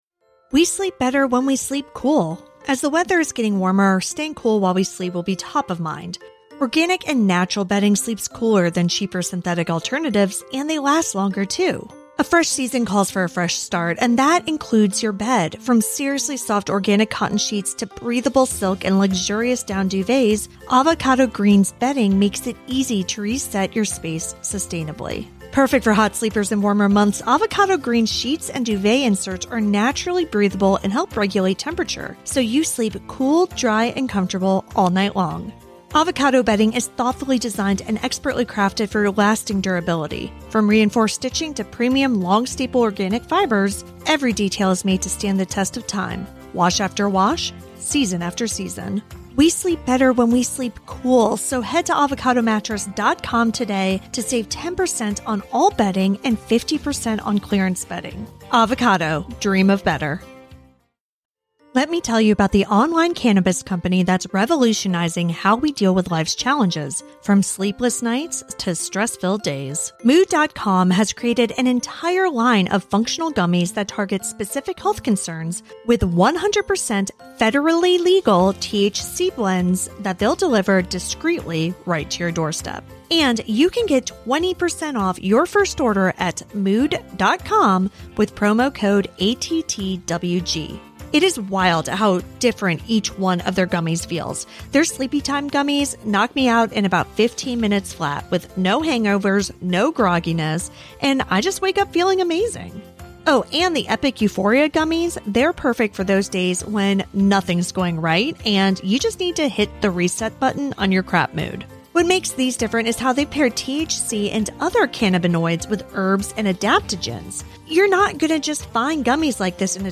Documentary, True Crime, Society & Culture